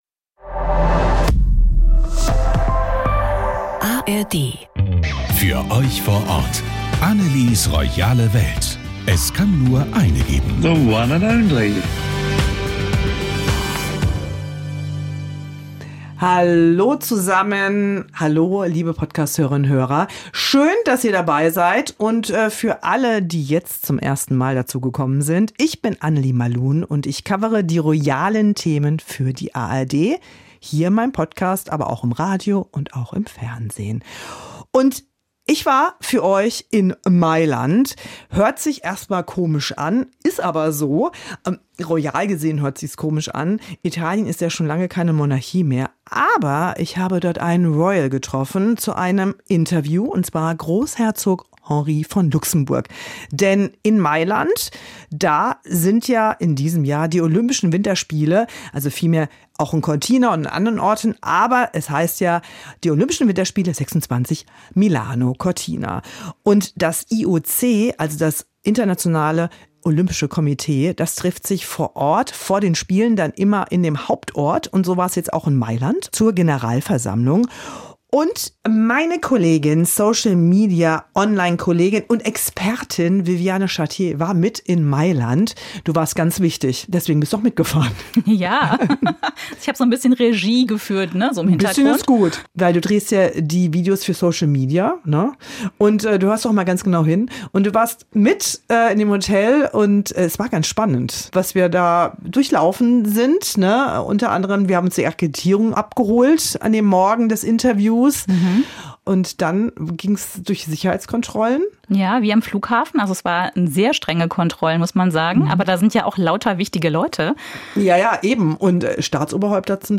Großherzog Henri von Luxemburg ist Mitglied im Internationalen Olympischen Komitee und ein großer Sportfan. Im Gespräch mit Royal-Expertin